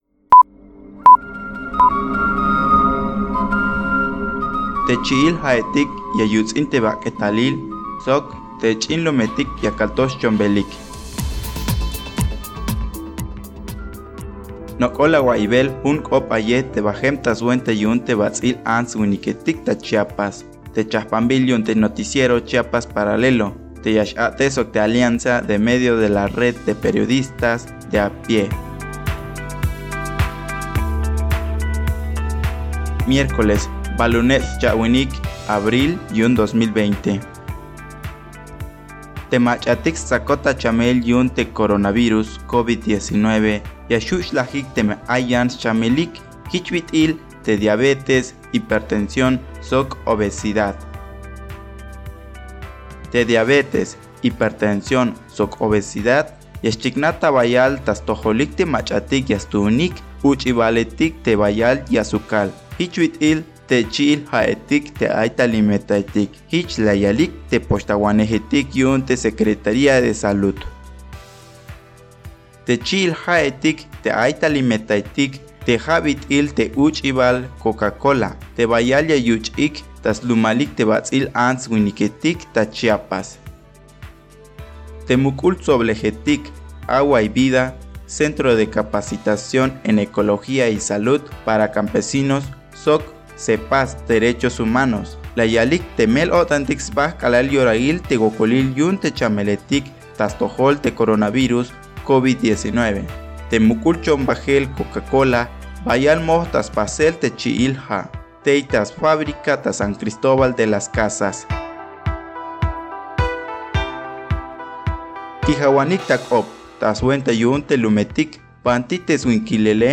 Nok’ol awaiybel, jun k’op ayej te bajem ta swenta yu’un te bats’il antswiniketik ta Chiapas, te chajpambil yu’unte Noticiero Chiapas Paralelo, te ya x-a’tej sok te Alianza de Medios de la Red de Periodistas de a Pie.
Traducción y locución